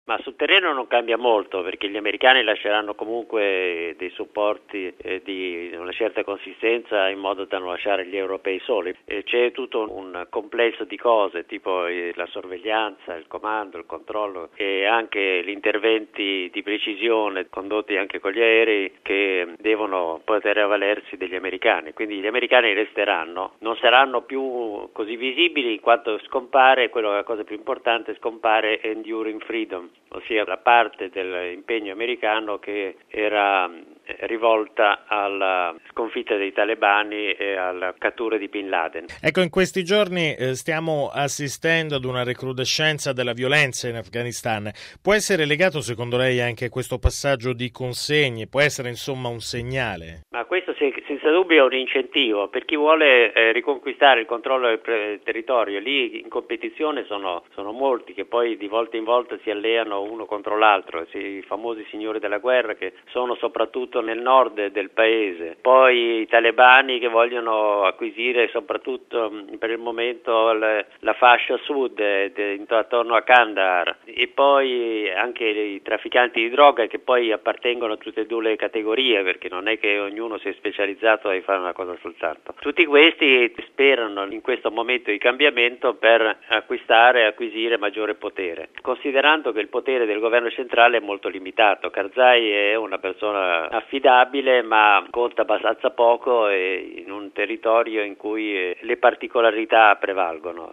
esperto di strategia militare